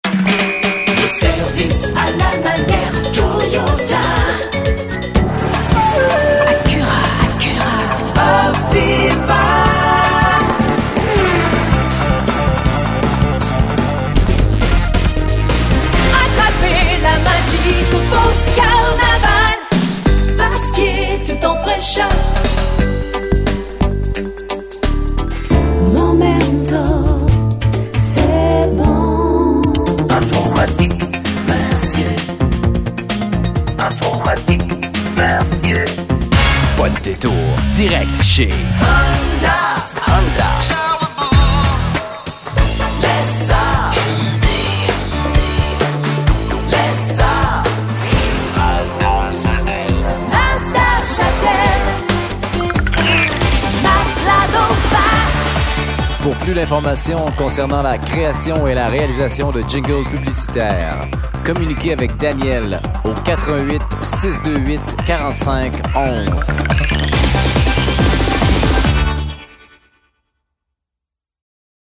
Démo publicitaire Démos additionnels...